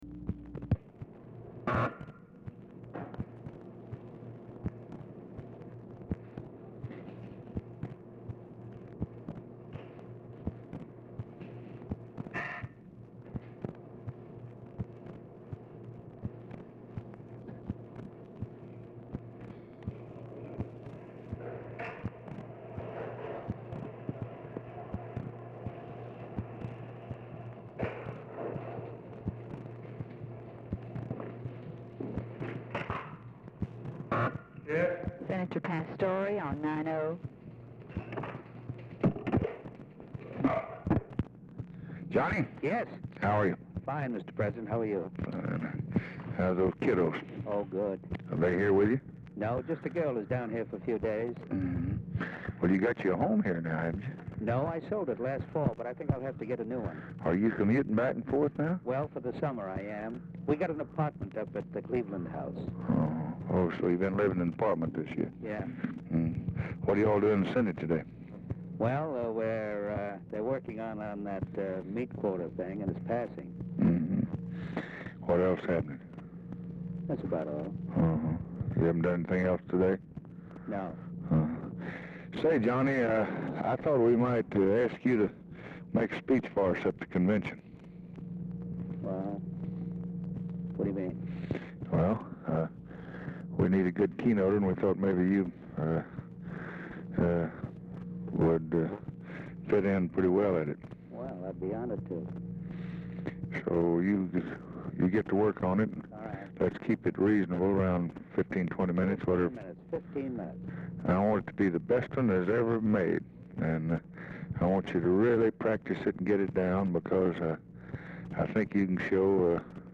Telephone conversation
OFFICE NOISE PRECEDES CONVERSATION
Format Dictation belt
Location Of Speaker 1 Oval Office or unknown location